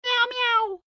AV_cat_med.ogg